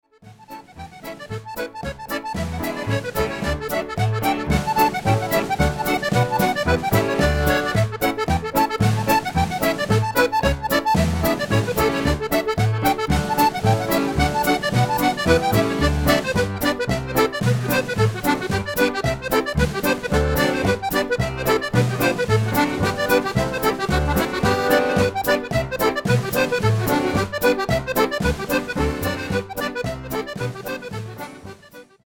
accordion
drums